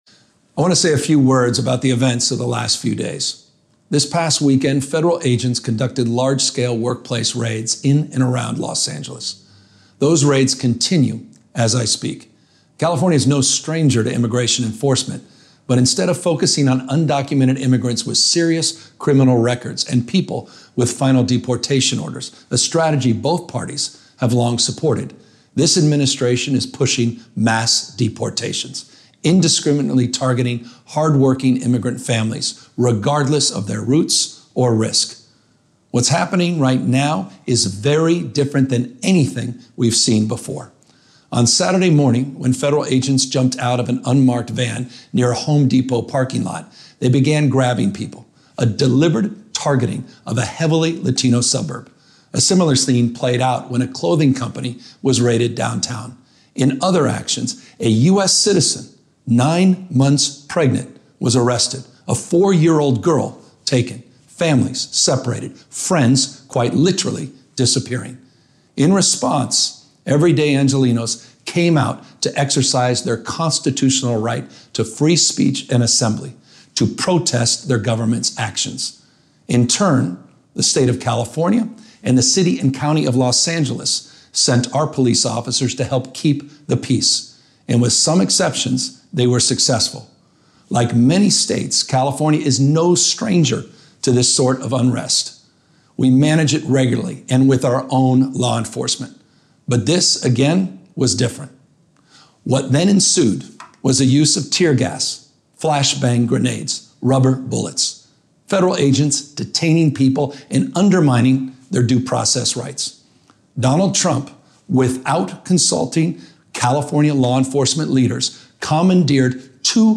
Audio mp3 of Address       Audio AR-XE mp3 of Address